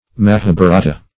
Search Result for " mahabarata" : The Collaborative International Dictionary of English v.0.48: Mahabarata \Ma*ha*ba"ra*ta\, Mahabharatam \Ma*ha*bha"ra*tam\, n. [Skr. mah[=a]bh[=a]rata.]